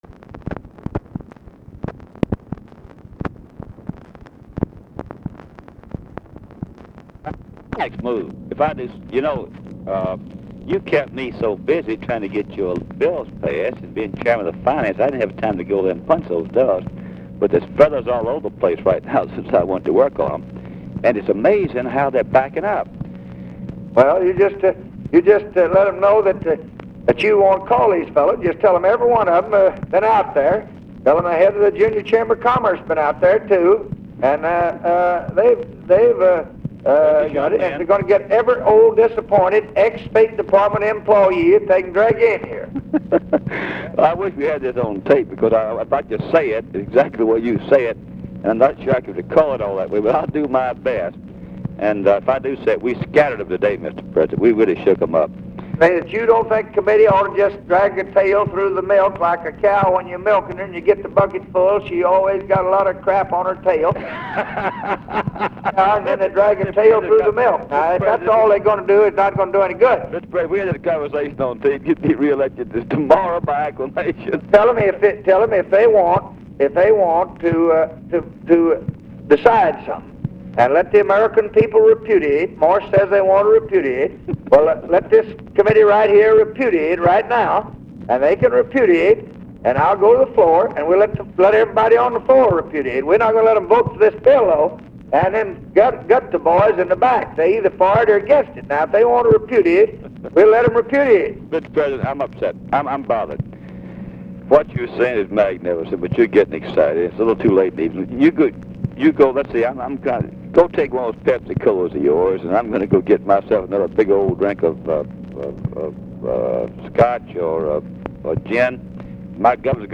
Conversation with RUSSELL LONG, February 18, 1966
Secret White House Tapes